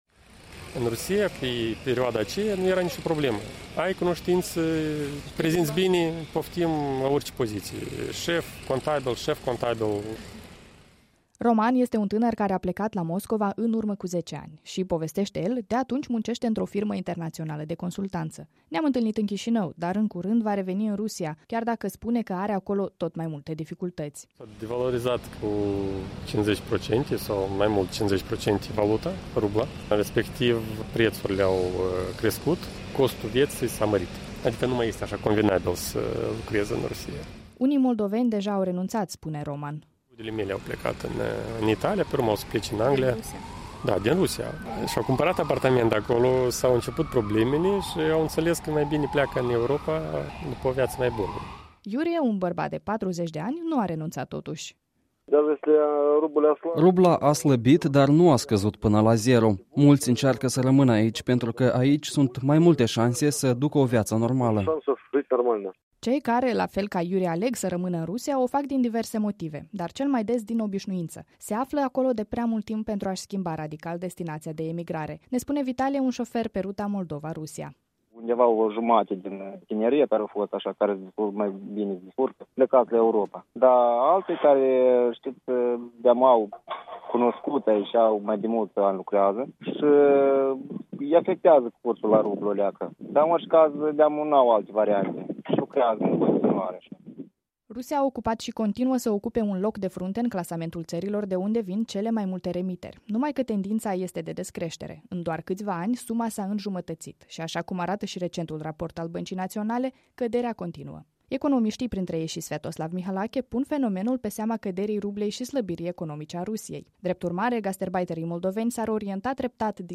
Un reportaj despre migrația moldovenilor și remitențe.